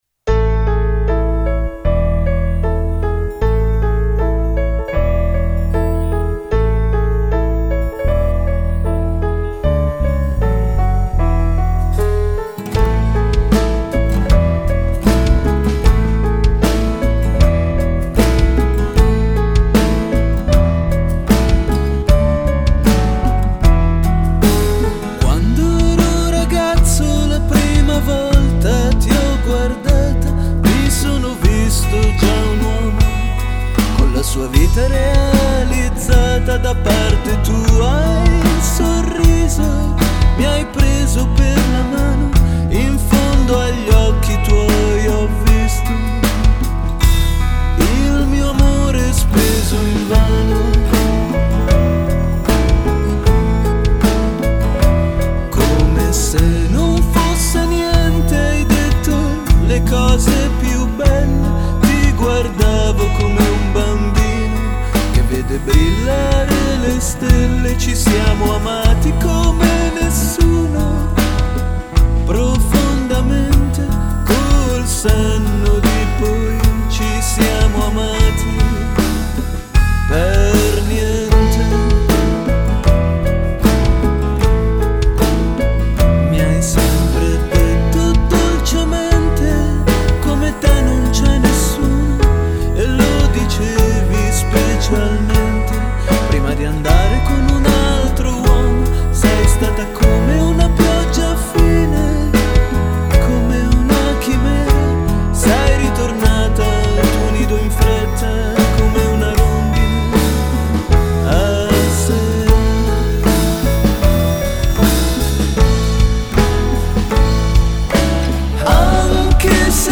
Slow pop